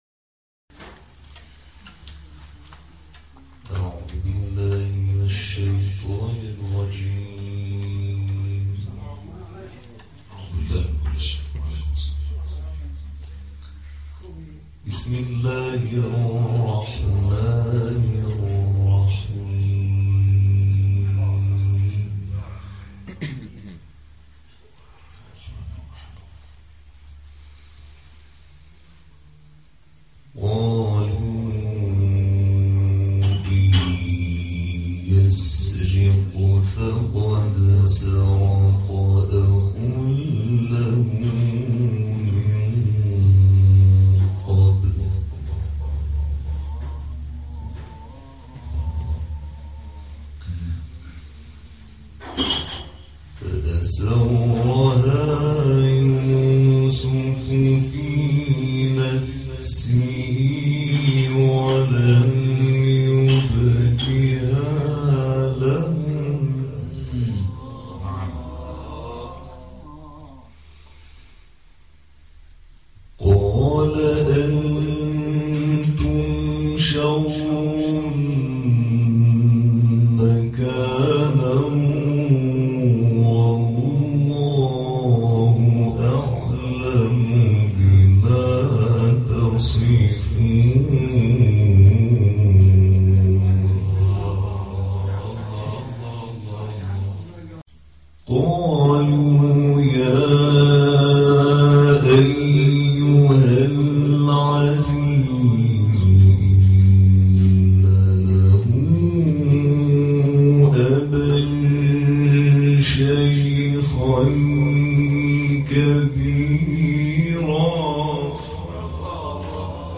در ادامه تلاوت های شب گذشته این جلسه قرآن و همچنین ابتهال حب الحسین(ع